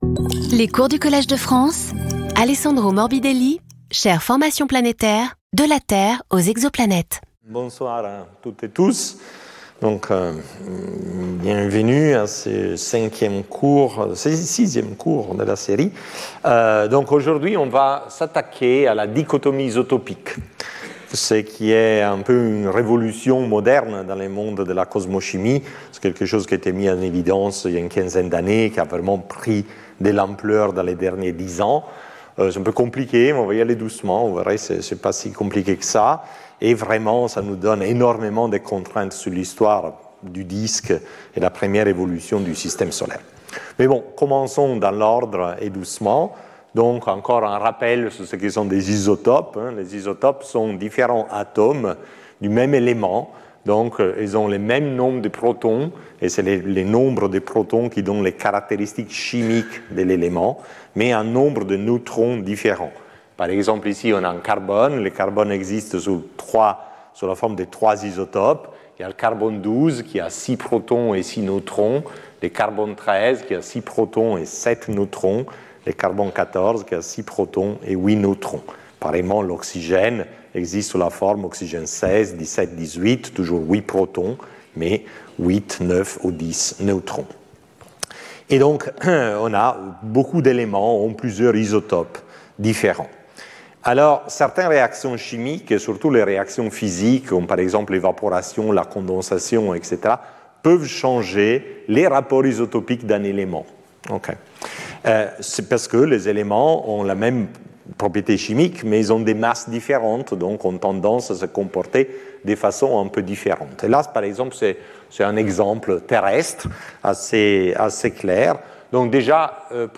Alessandro Morbidelli Professeur du Collège de France
Cours